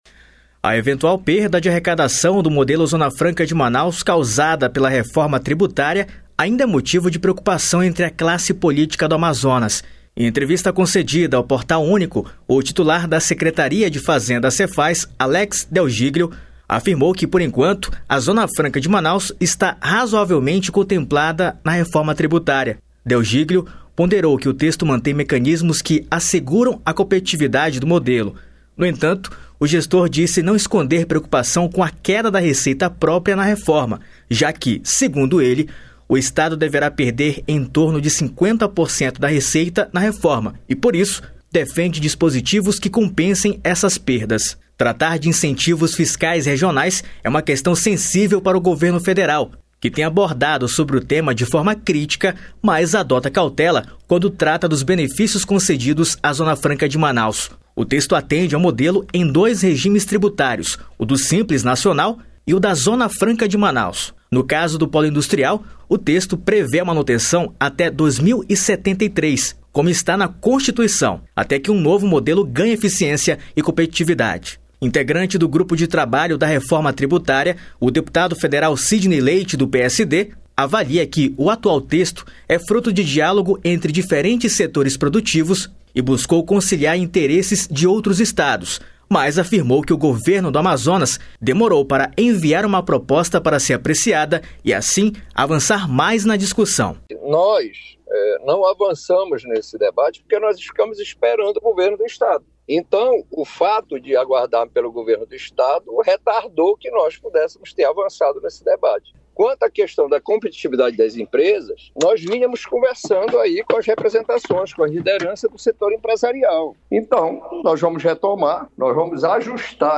Reportagem
Na avaliação do deputado federal Saullo Vianna (União Brasil) pequenos ajustes ainda podem ser feitos para garantir a competitividade do modelo: (Ouça)